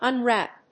発音記号
• / `ʌnrˈæp(米国英語)